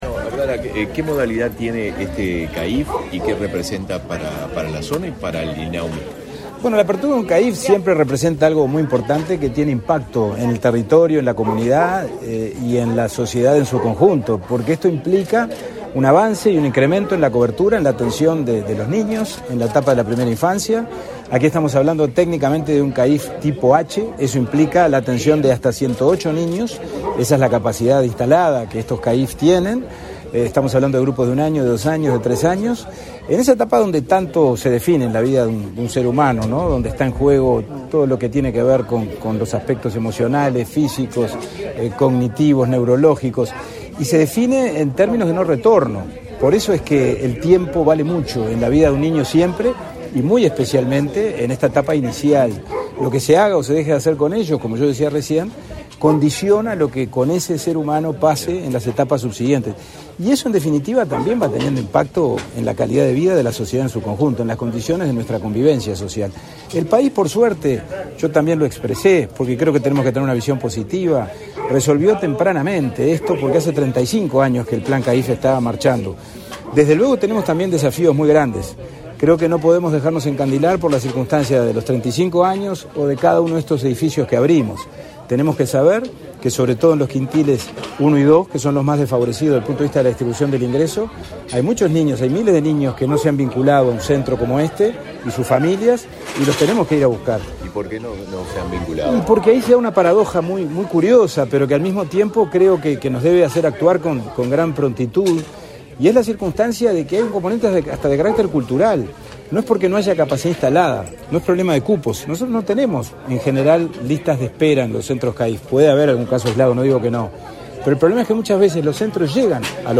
Declaraciones a la prensa del presidente del INAU, Pablo Abdala
Declaraciones a la prensa del presidente del INAU, Pablo Abdala 06/10/2023 Compartir Facebook X Copiar enlace WhatsApp LinkedIn El Instituto del Niño y el Adolescente del Uruguay (INAU) inauguró, este 6 de octubre, un centro de atención a la infancia y la familia (CAIF), en el barrio Puntas de Manga. Luego del evento, el presidente del INAU, Pablo Abdala, realizó declaraciones a la prensa.